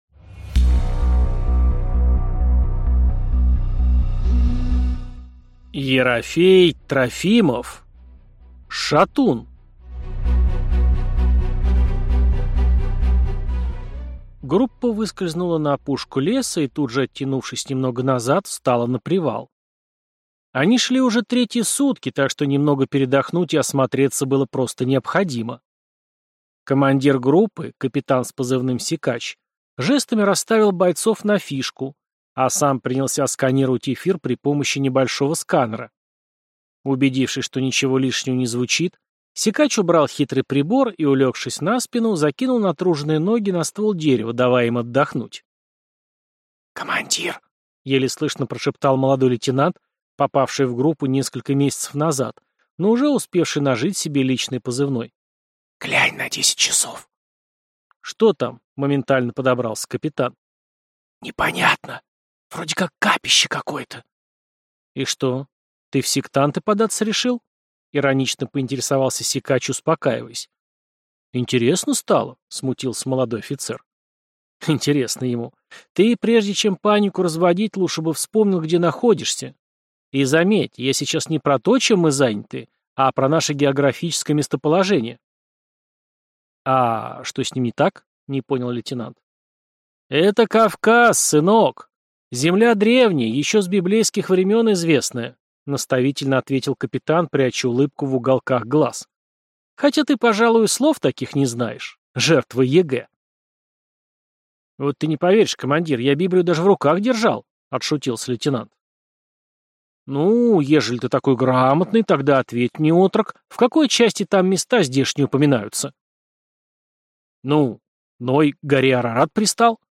Аудиокнига Шатун | Библиотека аудиокниг